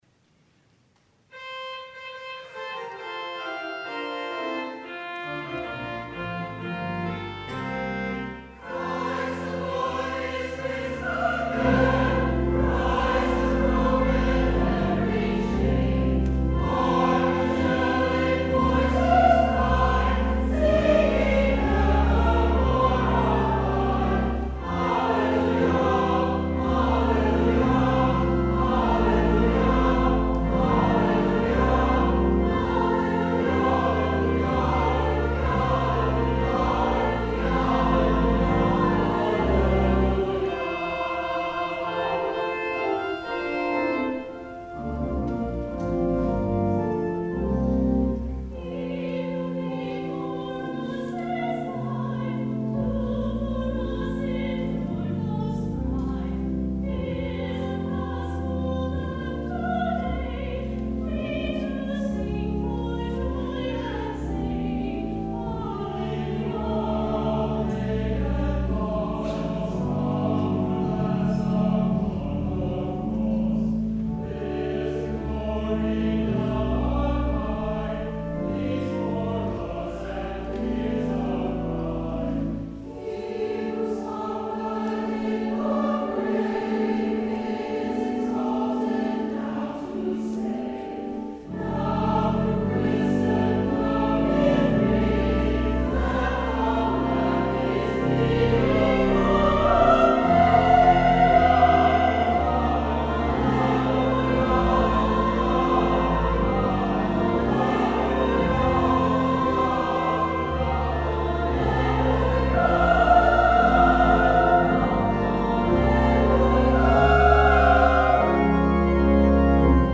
Chancel Choir
organ